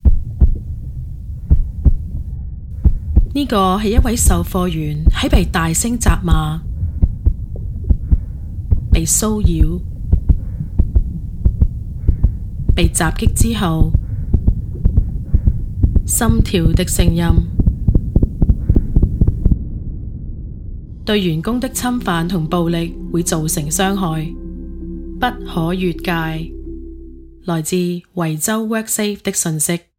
Female
My voice has been described as friendly, trustworthy, convincing, authoritative, authentic, enthusiastic, enticing, cut from a different cloth with a sassy certainty by my clients and peers.
Radio Commercials